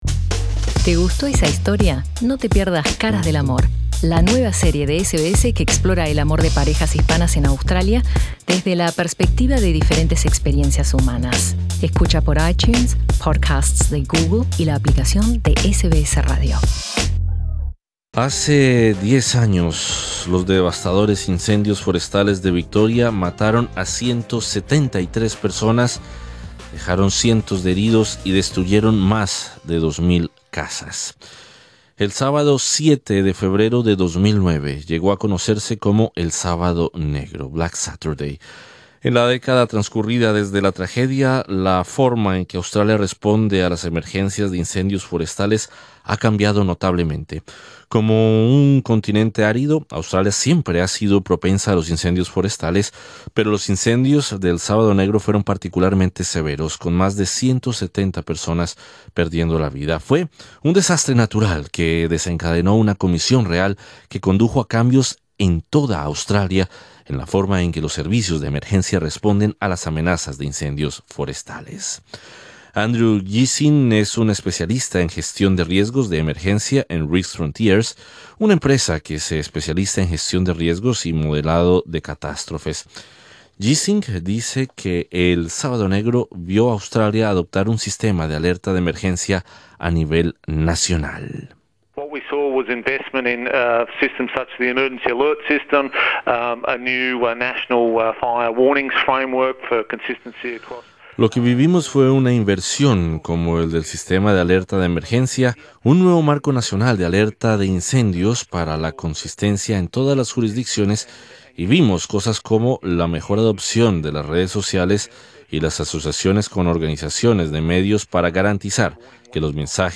Conversamos con una de las personas que vivió de cerca la tragedia del "Sábado Negro".